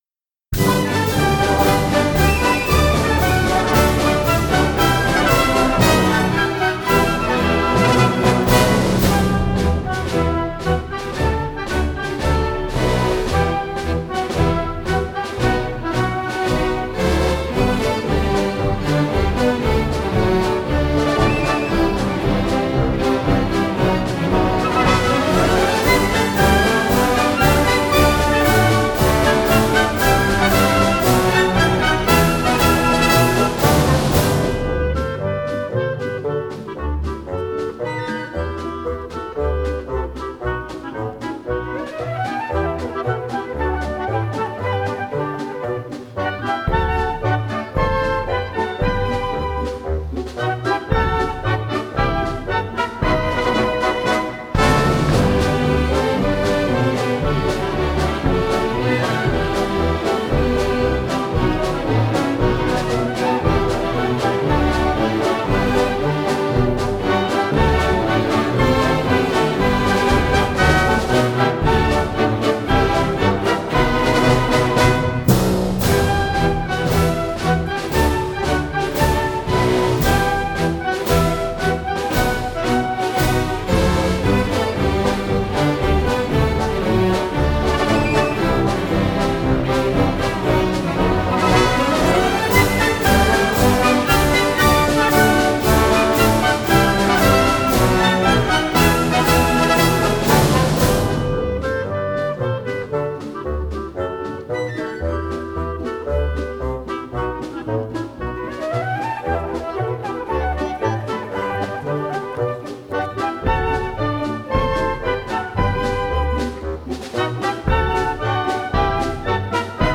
Pista sin coros del Himno completo (3:59)